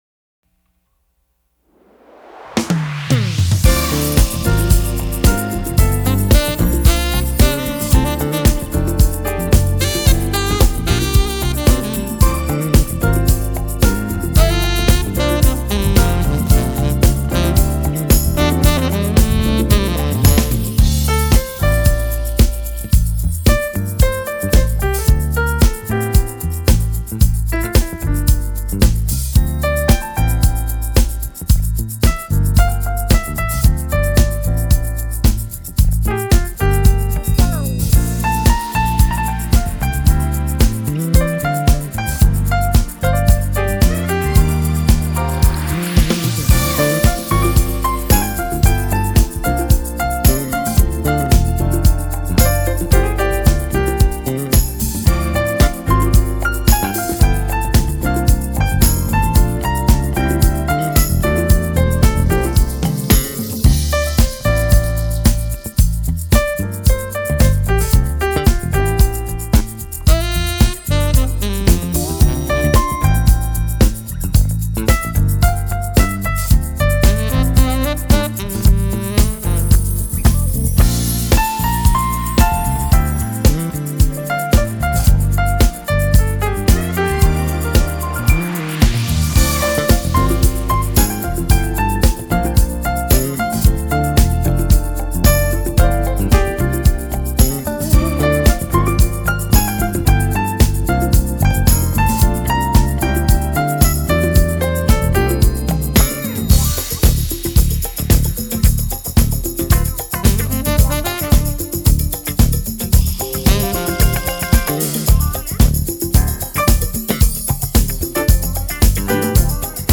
Жанр: Smooth Jazz